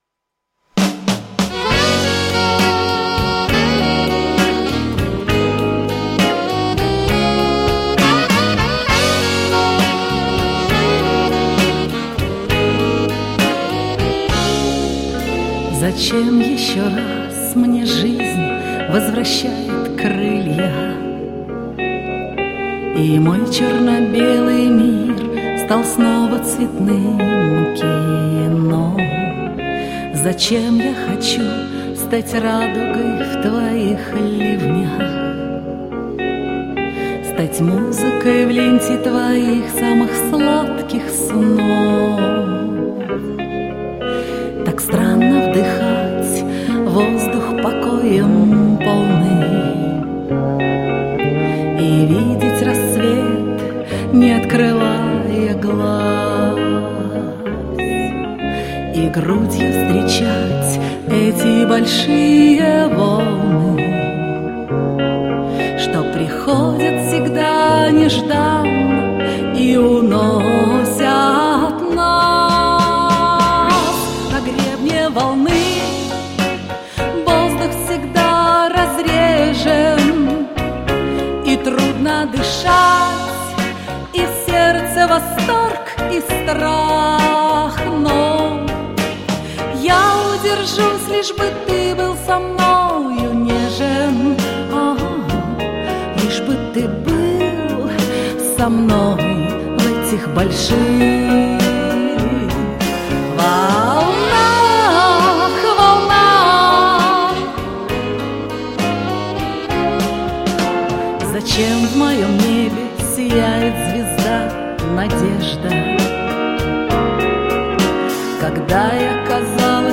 Девочки,обе молодцы,прекрасные голоса и чудесные исполнения!